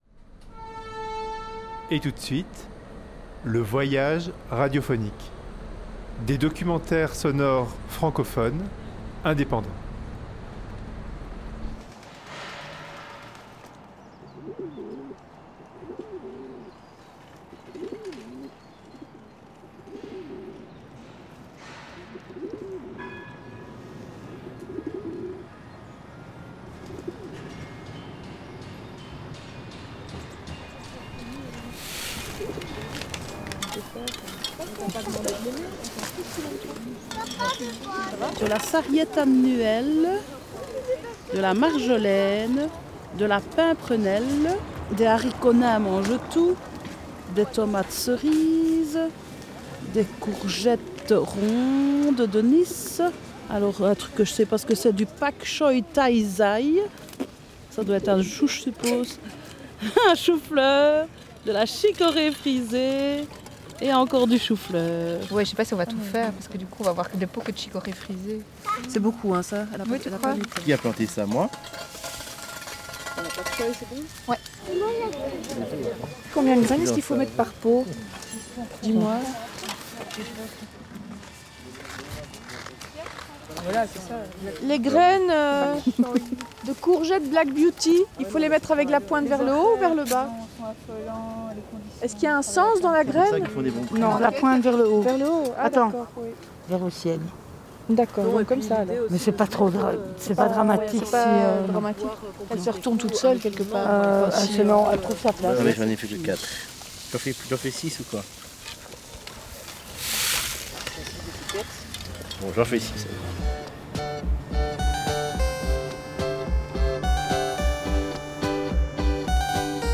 2016 Dans une zone densément peuplée de Bruxelles, proche de la gare TGV, un groupe d’habitants investit un des derniers terrains disponibles pour y créer un potager collectif. Sur une période de 3 ans, nous captons la vie de ce terrain.